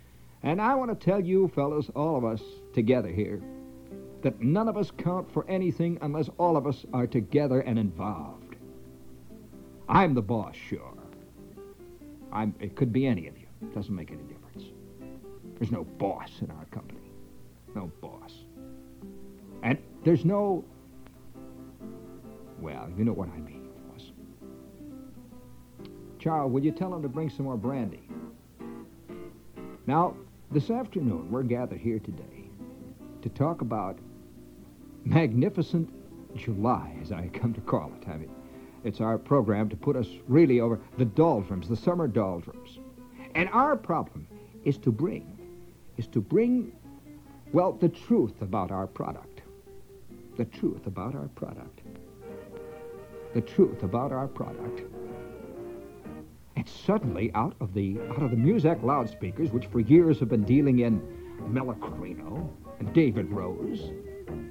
Genre: Classical, Cheap Guitar